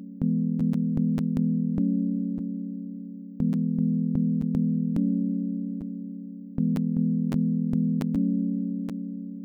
To be confident that it’s the computer and not my brain, I will record the sound from Pulseaudio using Audacity and play it back to check.
If you zoom in, you can see that the problem is sections of 0s between sections of audio.